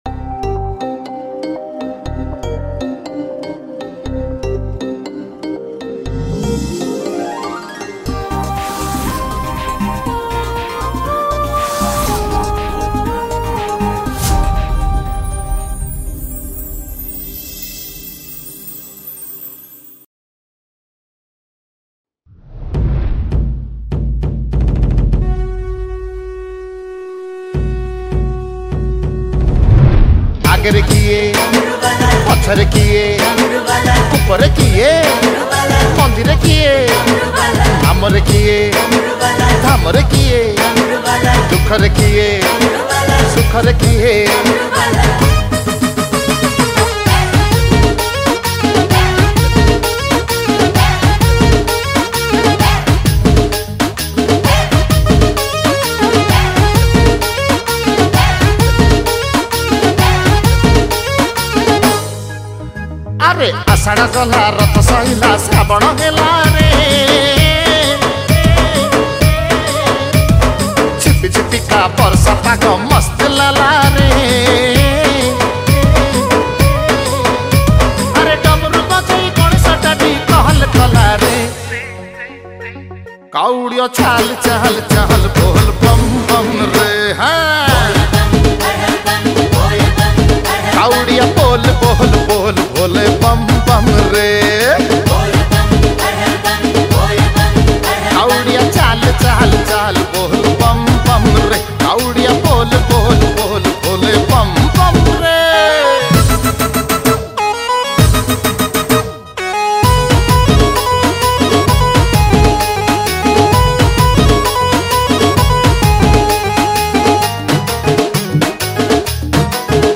Category : Bolbum Special Song